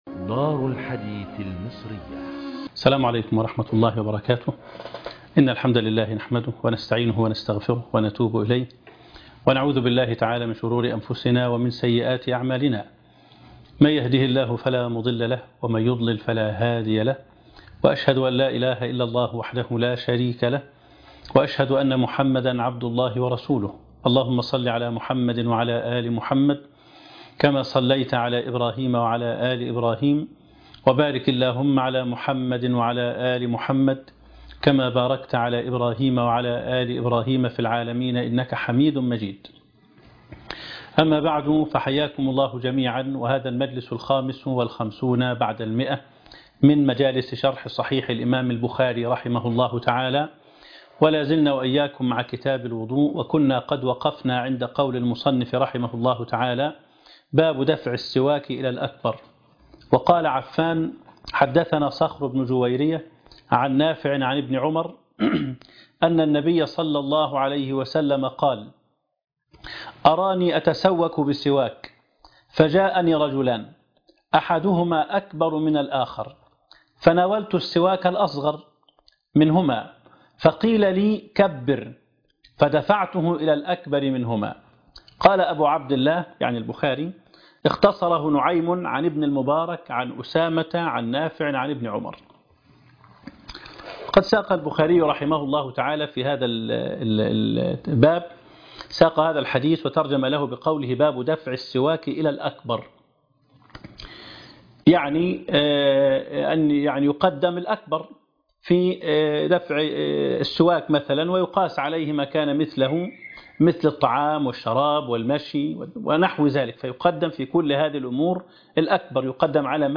الدرس (155) مجالس شرح صحيح الإمام البخاري